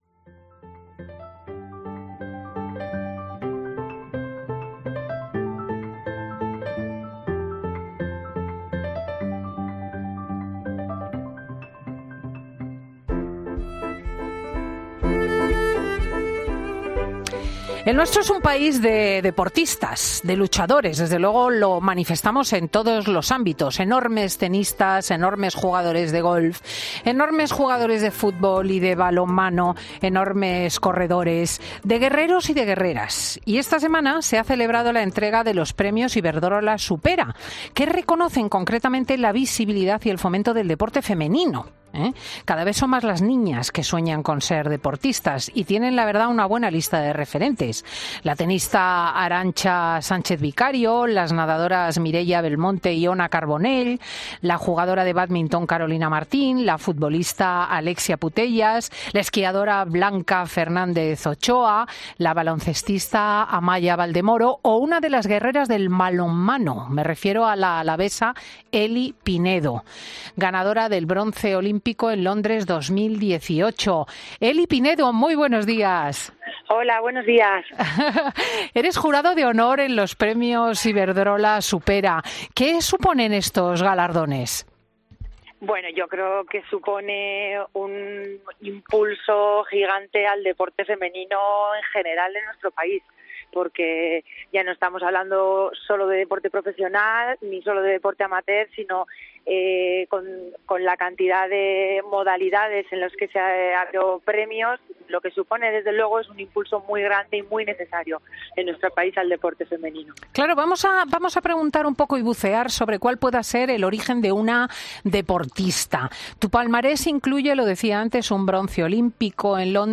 La exdeportista de balonmano ha sido una de los miembros del Jurado de Honor en los Premios Iberdrola Supera y en 'Fin de Semana' nos ha contado cómo le han impactado